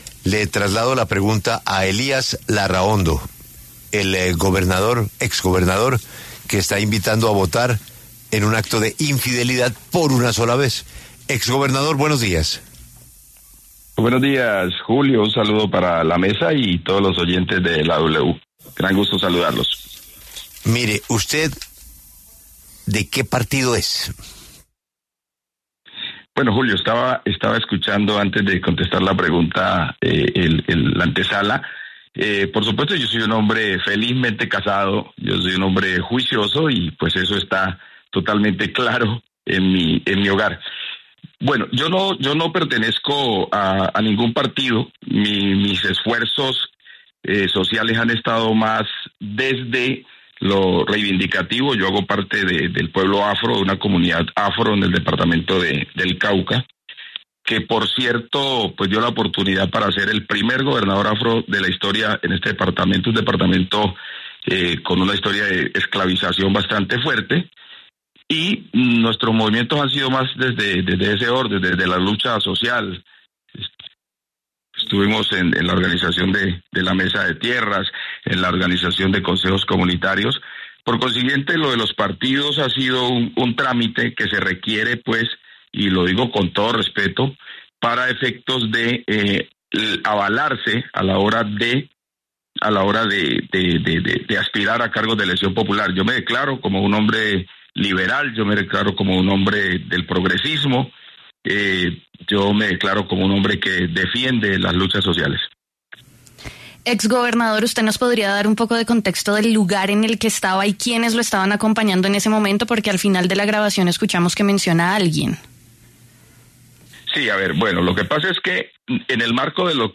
En entrevista con W Radio, el exgobernador aclaró que actualmente no es militante de ningún partido político: “yo me declaro como un hombre liberal, como un hombre del progresismo, como un hombre que defiende las luchas sociales”.